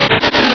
Cri d'Arcko dans Pokémon Rubis et Saphir.
Cri_0252_RS.ogg